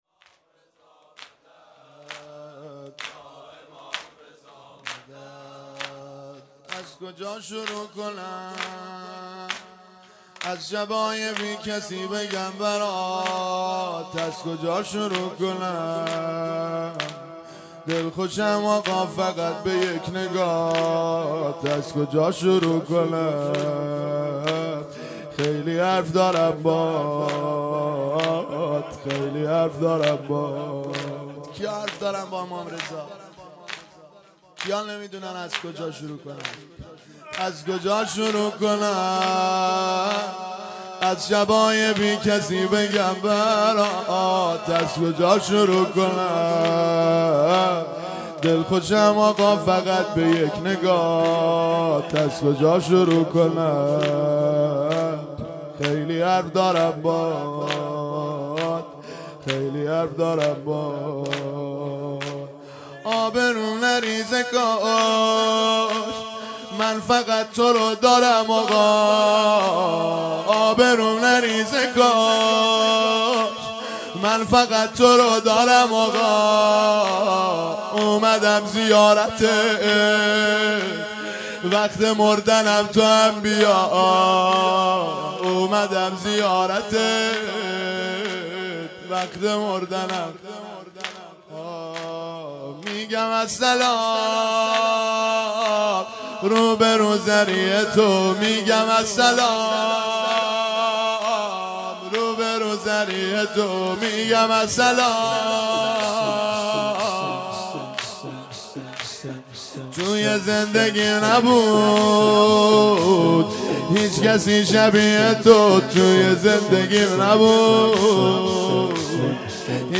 مجموعه صوتی عزاداری شام شهادت امام رضا (علیه السلام)